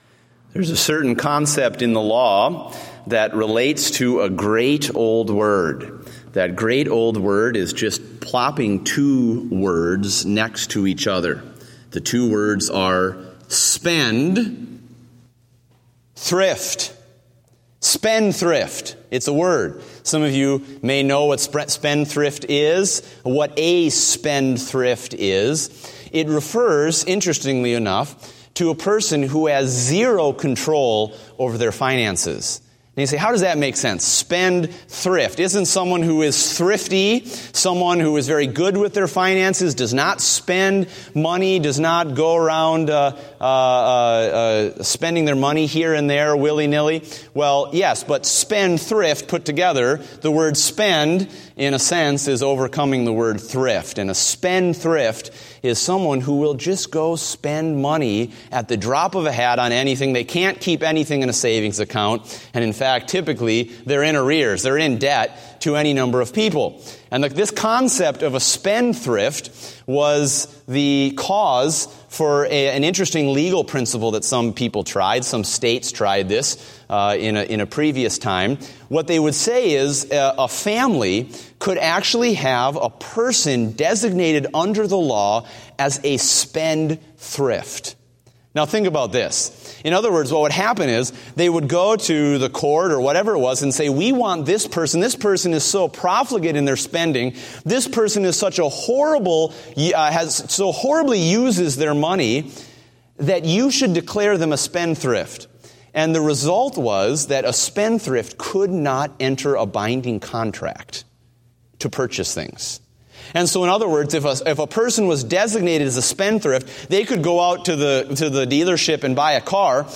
PM Service